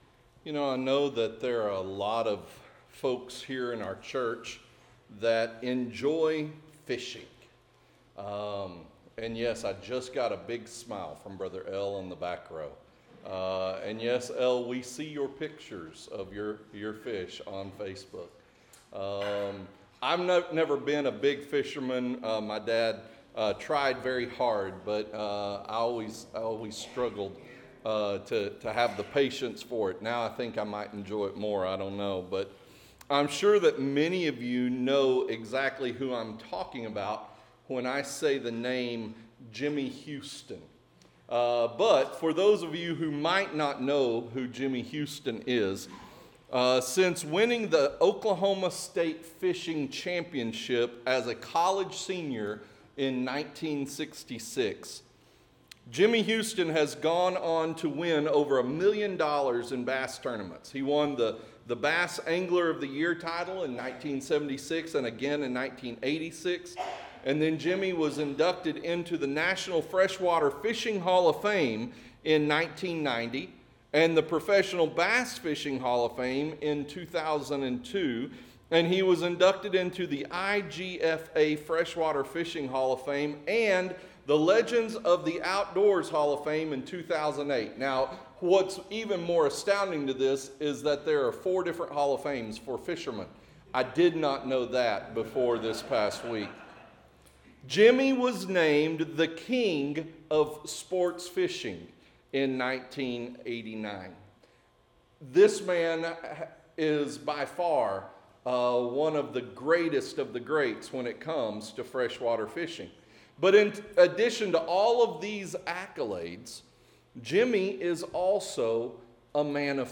Interactive Sermon Notes Recharge – Full PDF Download Recharge – Week 8 Download Series: Nehemiah: Rebuild & Renew , REBUILD: Restoring the Broken